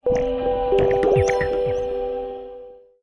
0405_PowerOff.mp3